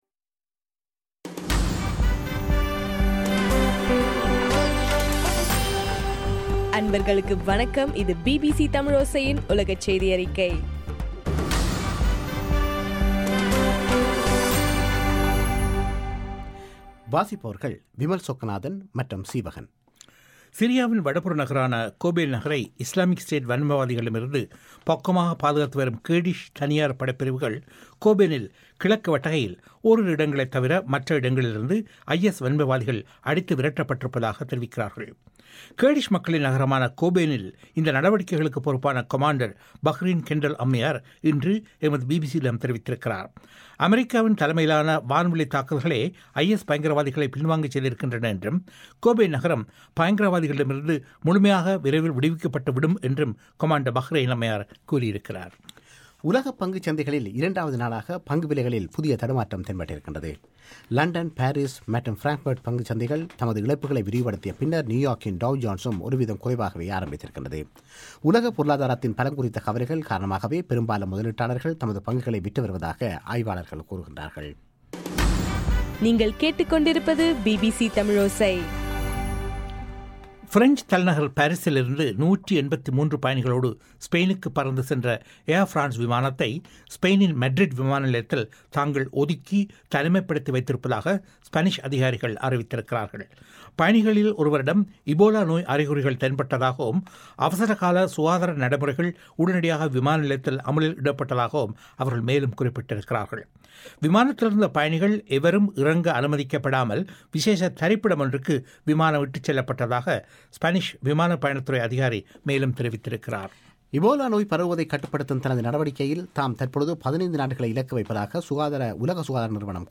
அக்டோபர் 16 - பிபிசி உலகச் செய்திகள்